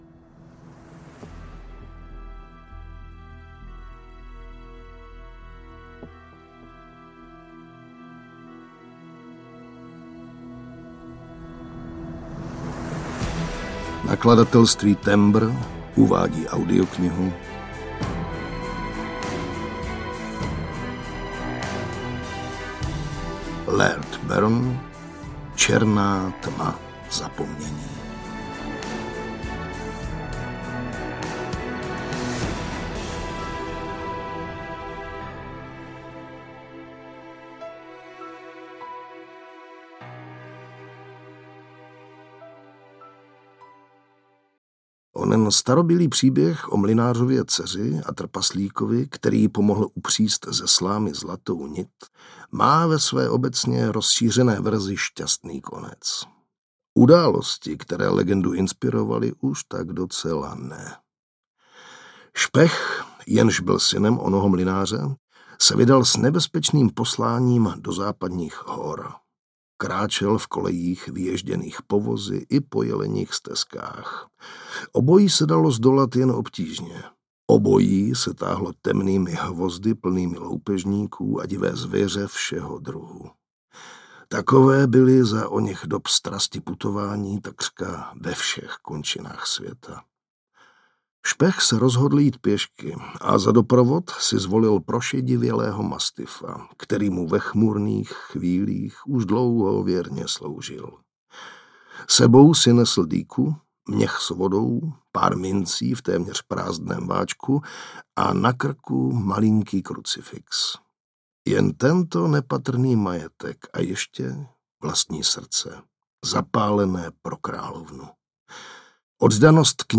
Černá tma zapomnění audiokniha
Ukázka z knihy